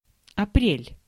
Ääntäminen
IPA: /ˈhuh.tiˌkuː/